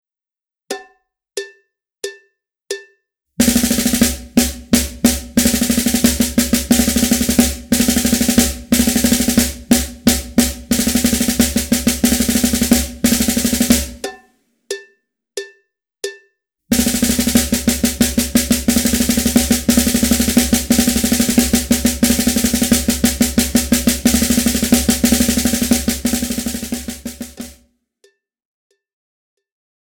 Besetzung: Schlagzeug
03 - 9-Stroke-Roll
03_-_9-Stroke-Roll.mp3